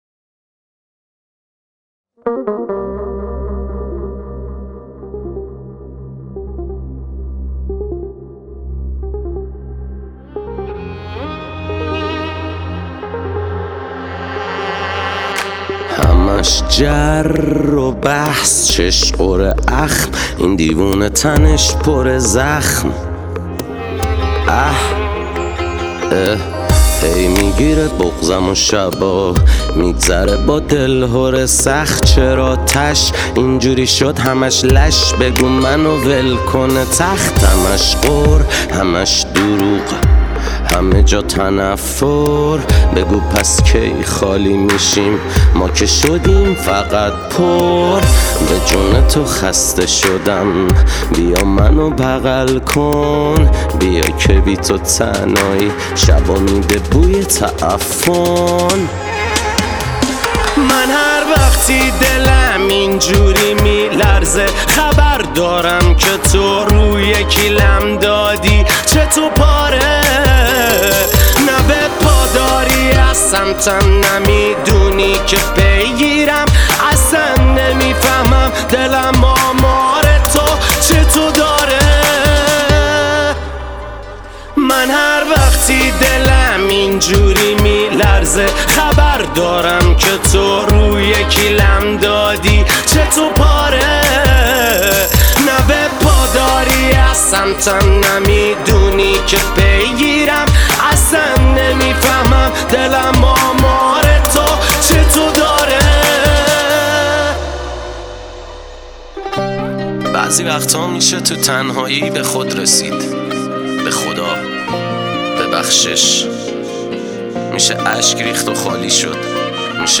تک آهنگ
آراَندبی